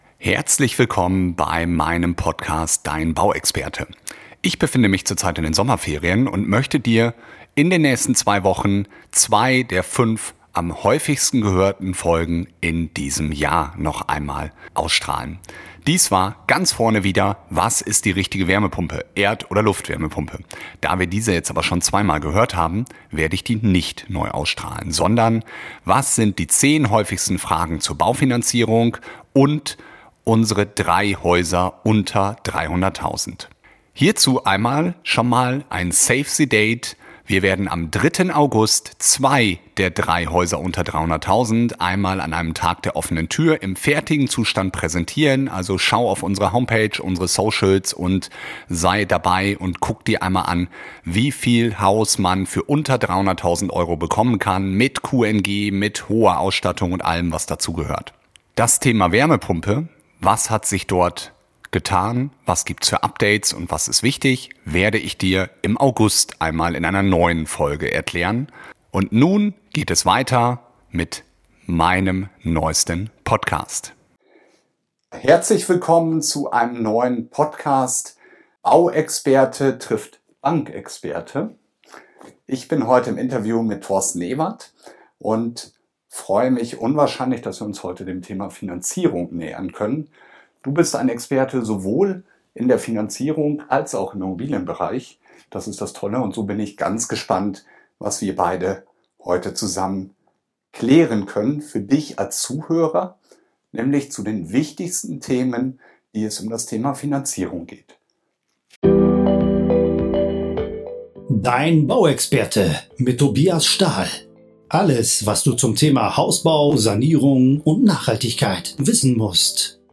Recap: 10 Fragen zur Baufinanzierung